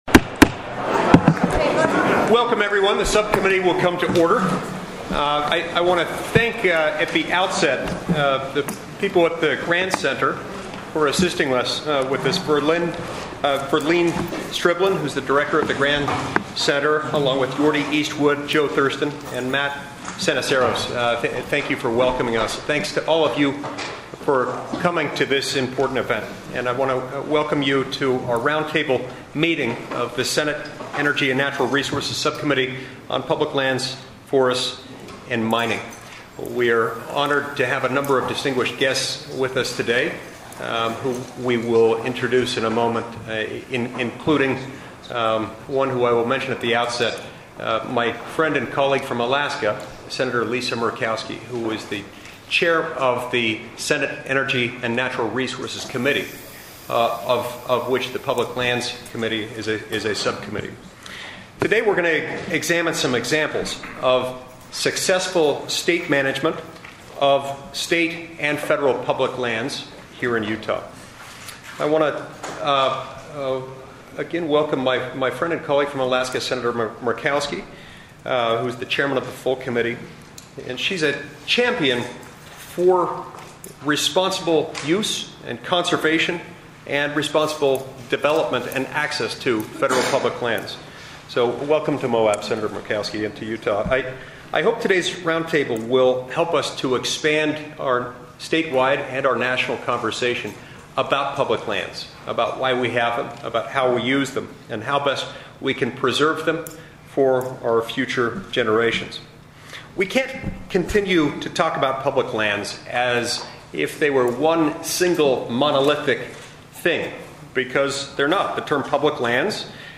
The roundtable will be held on Friday, October 25, 2019, at 3:00 p.m. MDT (5:00 p.m. EDT) at the Grand Center Senior Citizens Center, 182 North 500 W, in Moab, Utah.